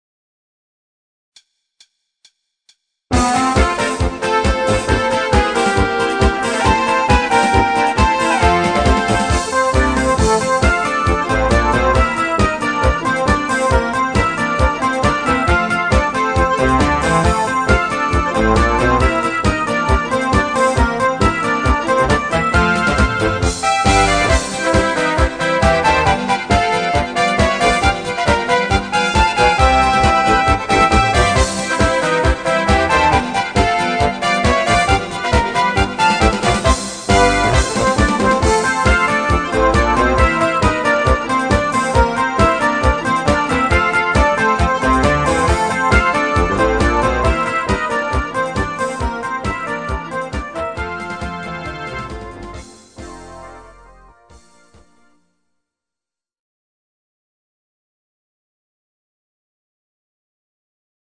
Genre(s): Volksmusik  |  Rhythmus-Style: Polka